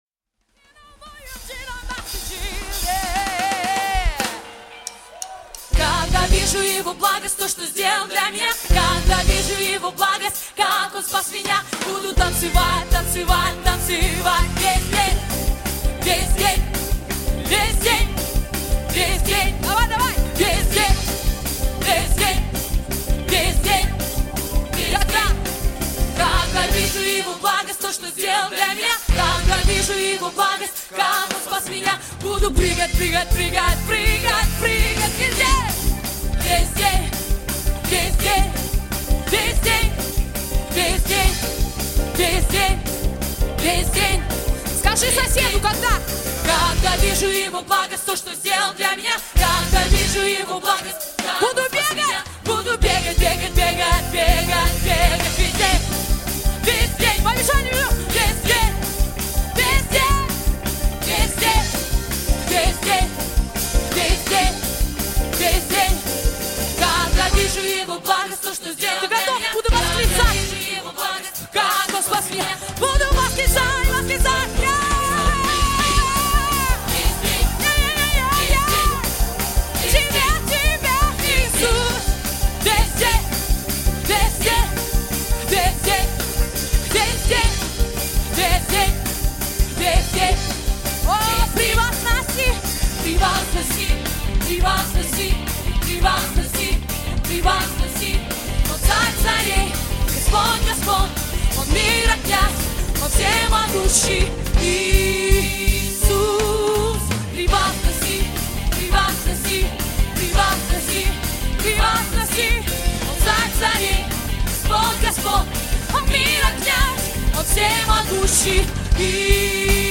песня
360 просмотров 448 прослушиваний 18 скачиваний BPM: 150